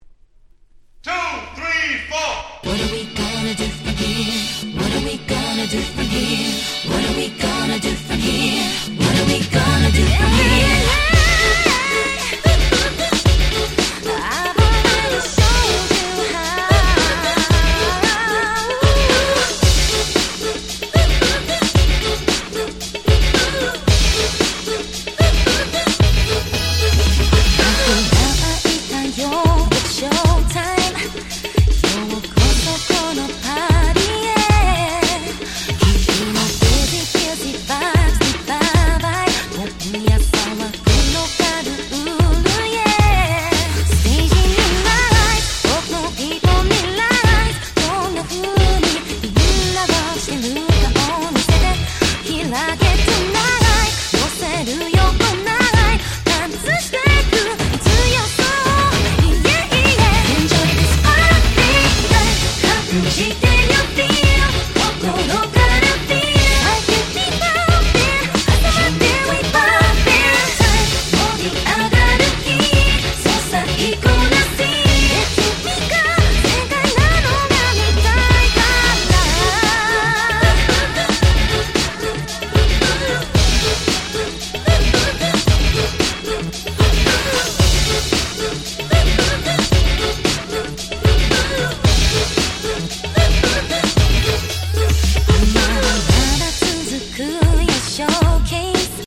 06' Very Nice Japanese R&B !!